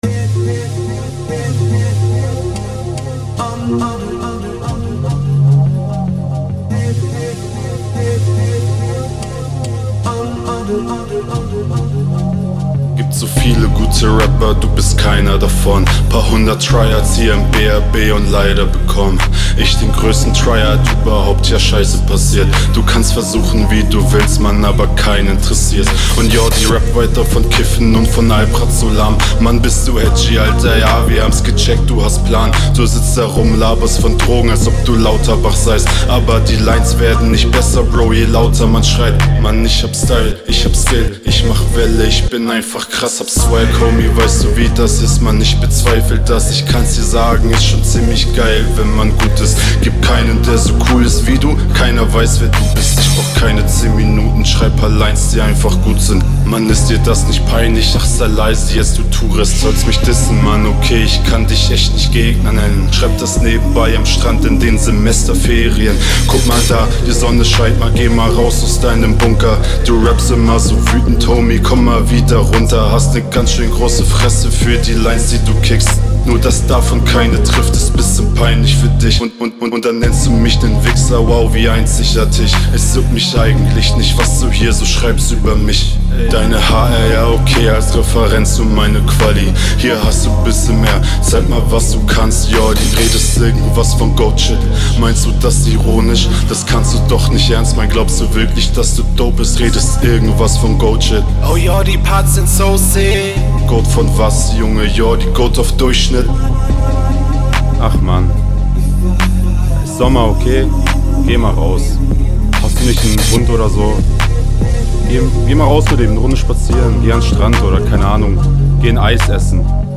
Interessanter Beat, ich hätte mir gewünscht, dass du da v.a. flowtechnisch mehr draus machst.